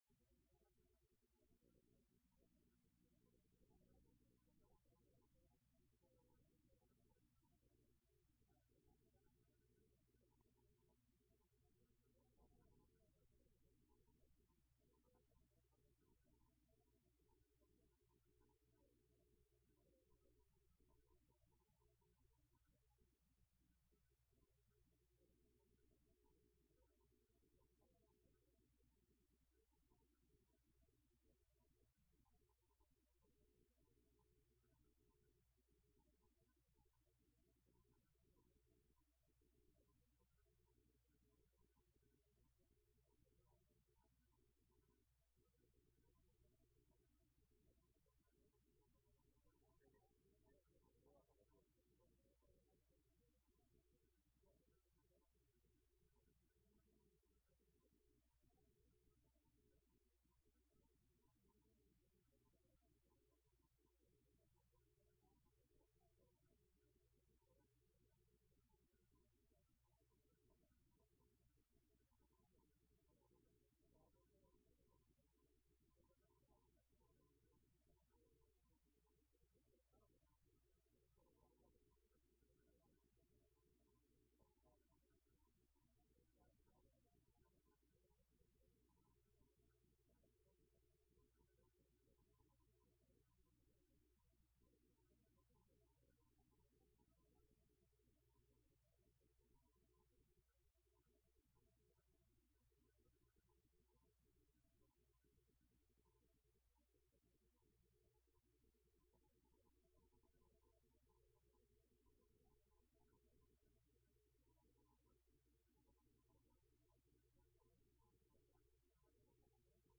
Informativo con toda la actualidad de Jaén